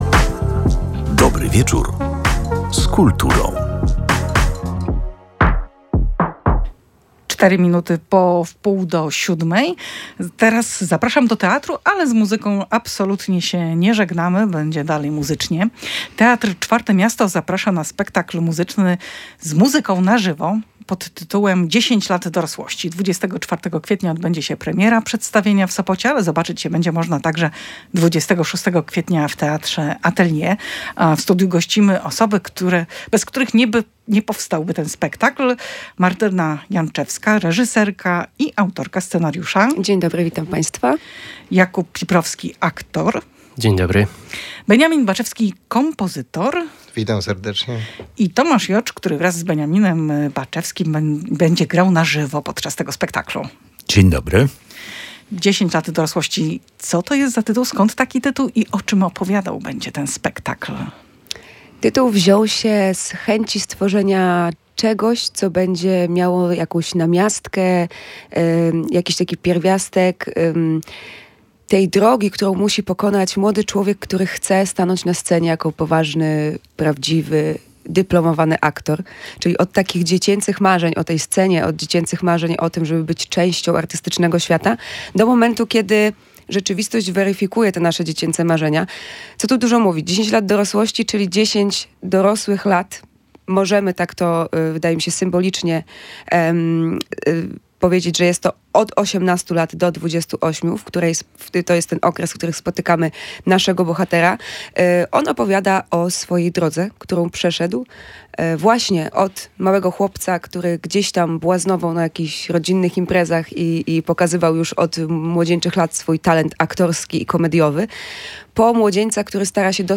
Podczas rozmowy w studiu Radia Gdańsk wszyscy uczestnicy podkreślali, że taka droga – od marzeń, ambicji, po walkę o wykonywanie zawodu – dotyczy wszystkich zawodów artystycznych.